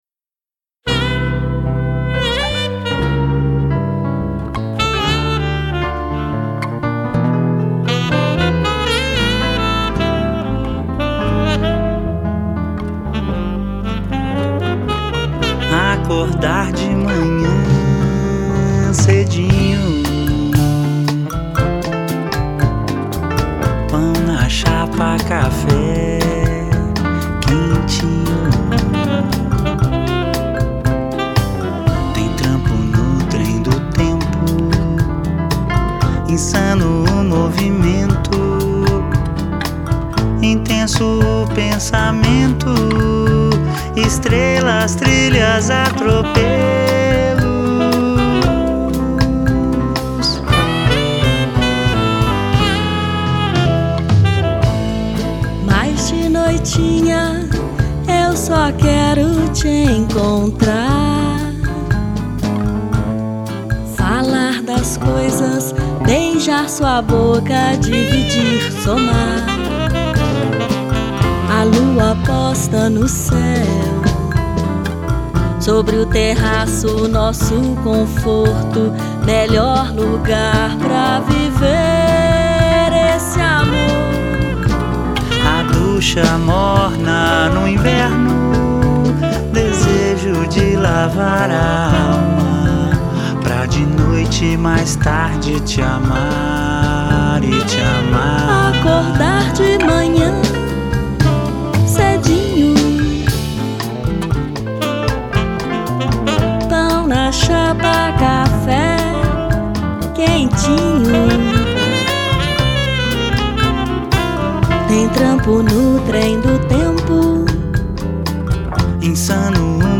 Violão e voz
Sax
Bateria
Baixo
Percussão
Piano